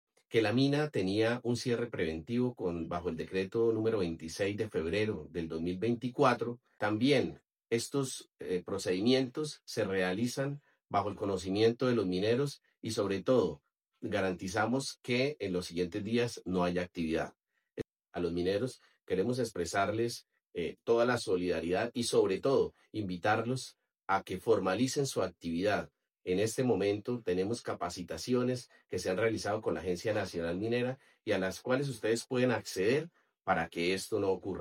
Alcalde Landazuri, Carlos Morales